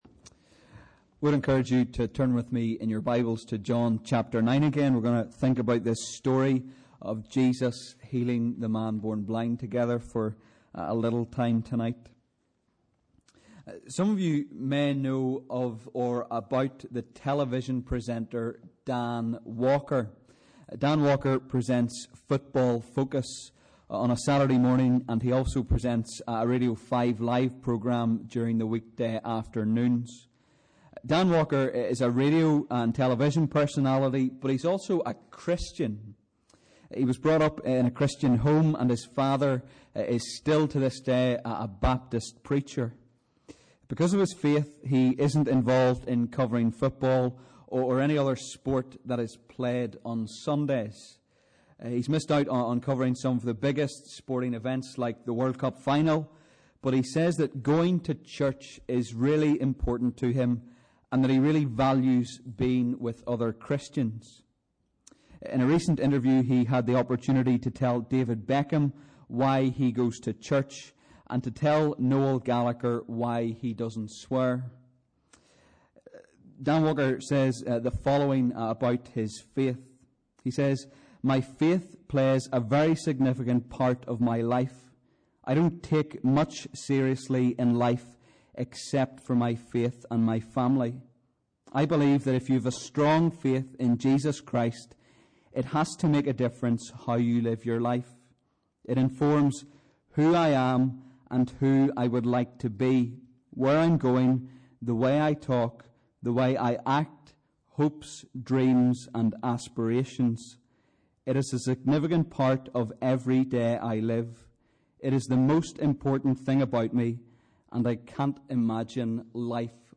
Sunday 5th July Evening Service @ 7:00pm Bible Reading: John 9
← Newer Sermon Older Sermon →